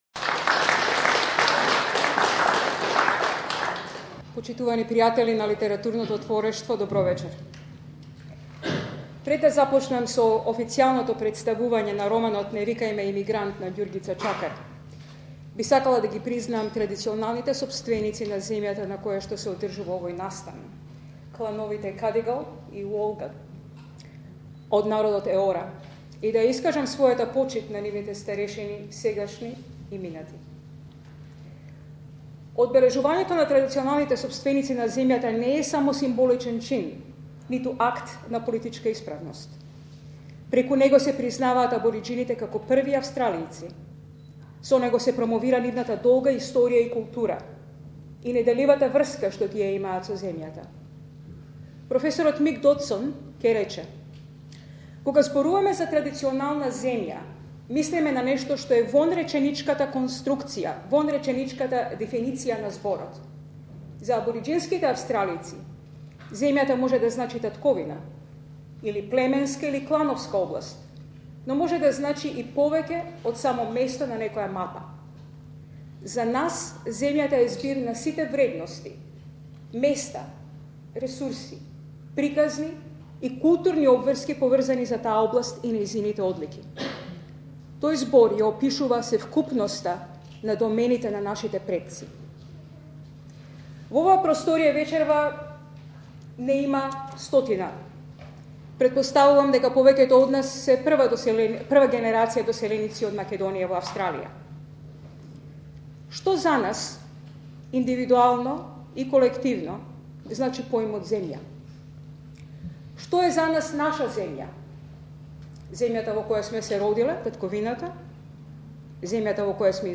first roman launch in Sydney